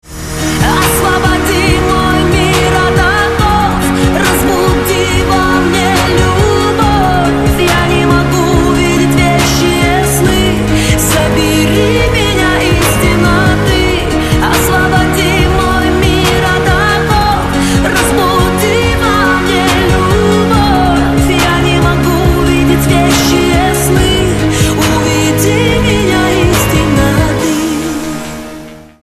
• Качество: 128, Stereo
поп
красивые
женский вокал
печальные